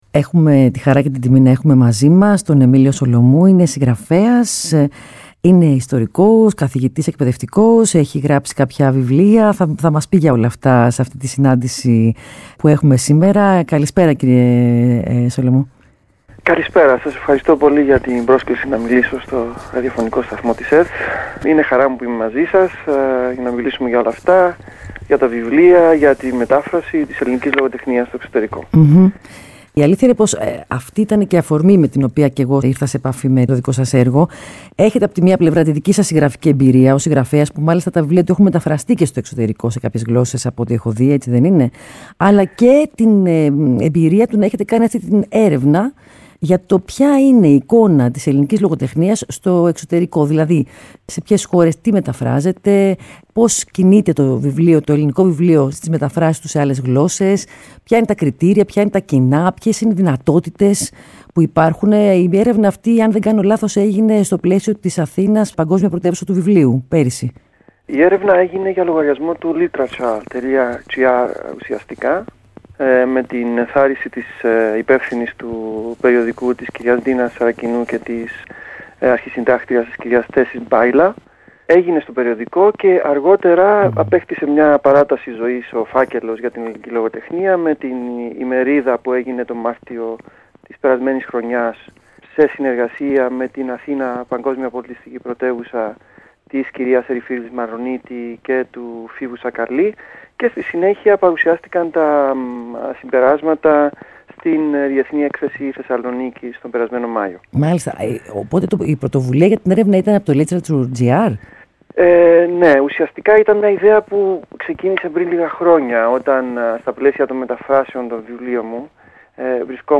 συνέντευξή